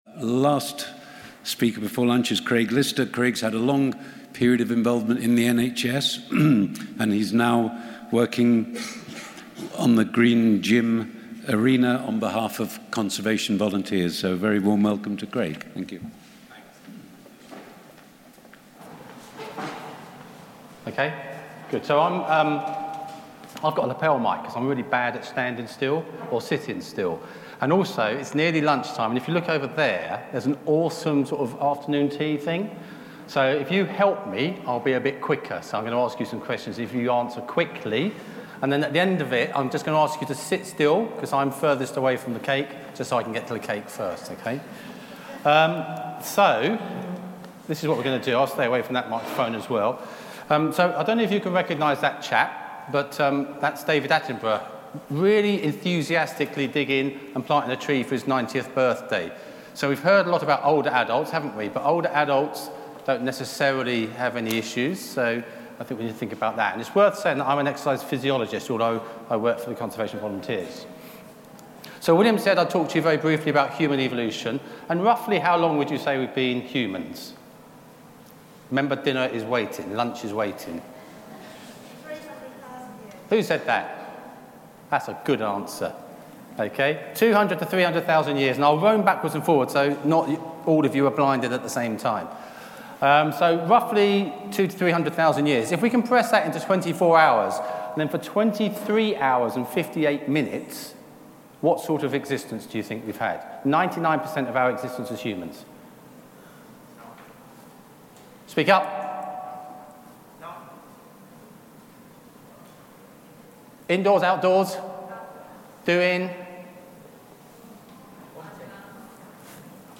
Recorded on 28 January 2019 during 'health, wellbeing and the environment' at The Isla Gladstone, Liverpool.